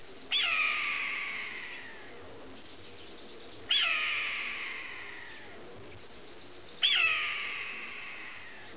redhawk.wav